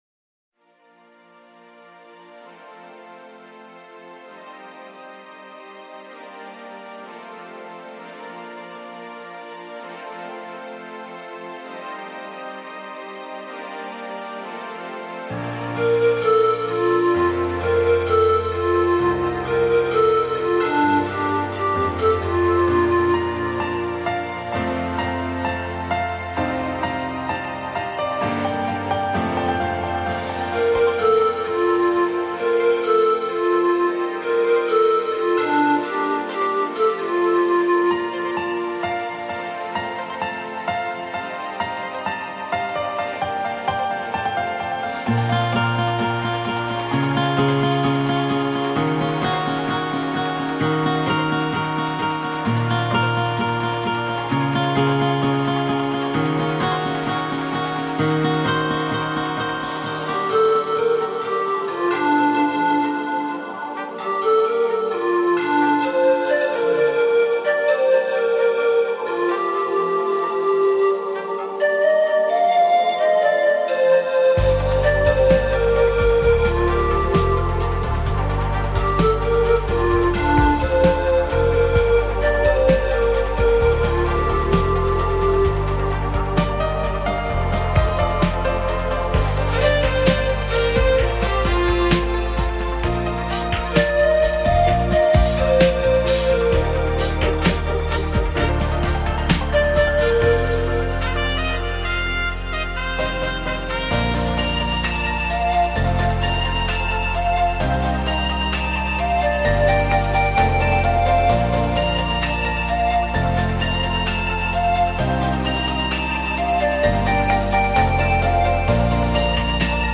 STRUMENTALE